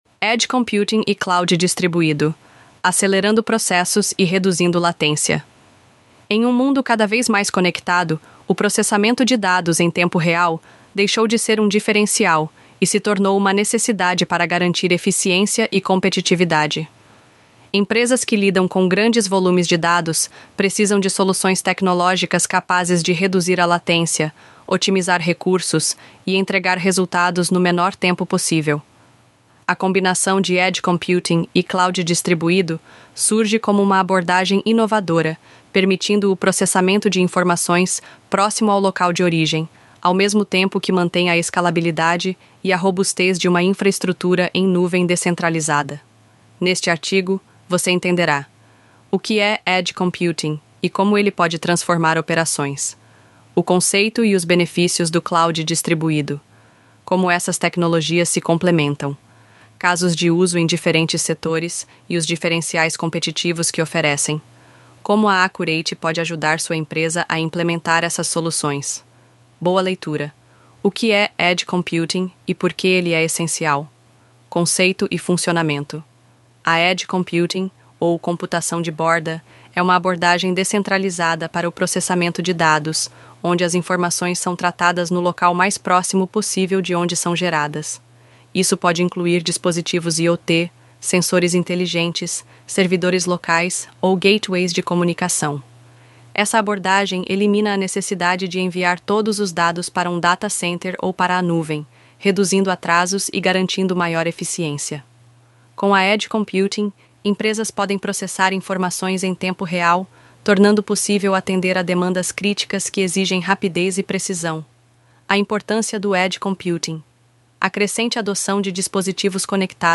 Texto-sobre-Computacao-de-Borda-Edge-e-Cloud-Distribuido-Narracao-Avatar-Rachel-ElevenLabs.mp3